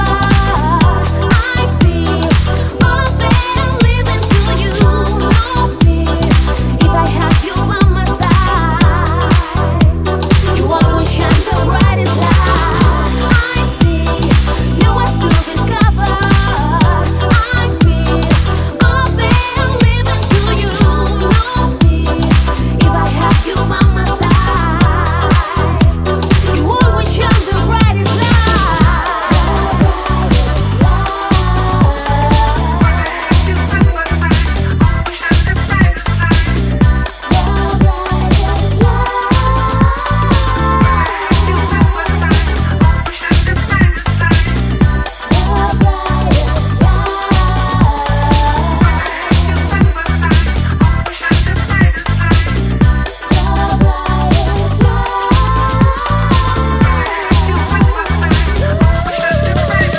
ジャンル(スタイル) SOULFUL HOUSE